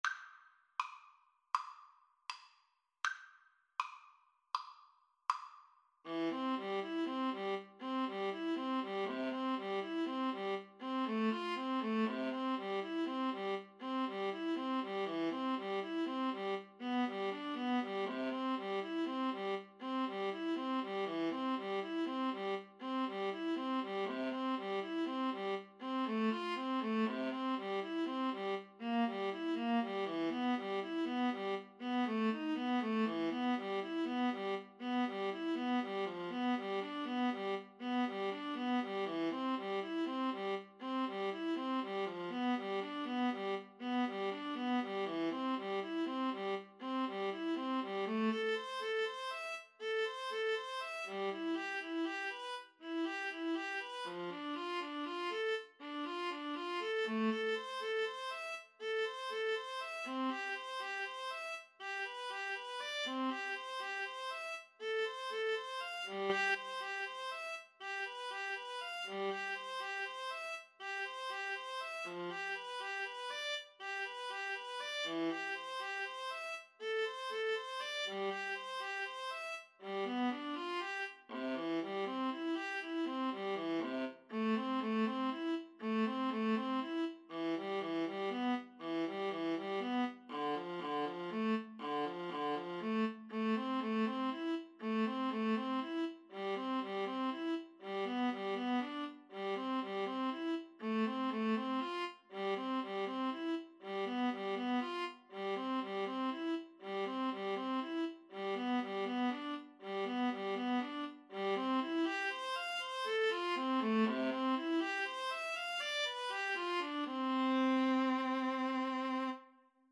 Free Sheet music for Clarinet-Viola Duet
C major (Sounding Pitch) (View more C major Music for Clarinet-Viola Duet )
Andante